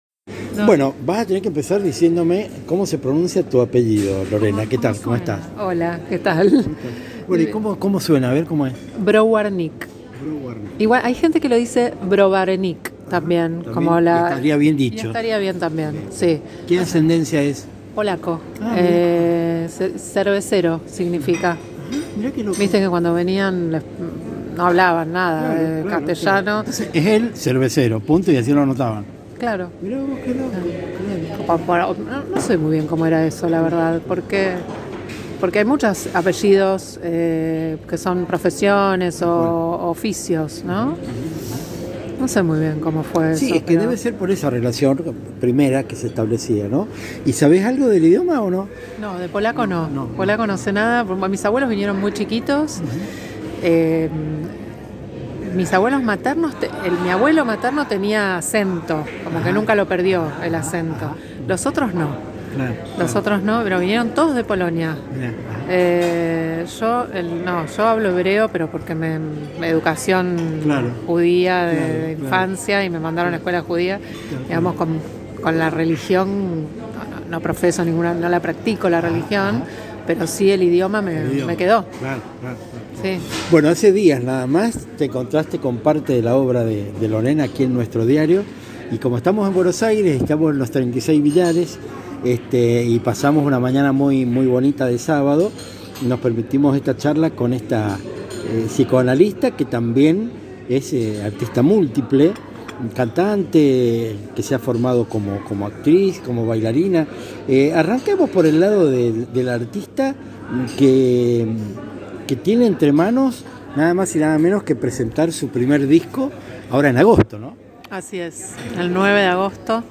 Sumergidos ya en la charla, el derrotero traté de hacerlo divertido, en el sentido profundo y amplio del término.